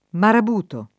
marabutto [ marab 2 tto ]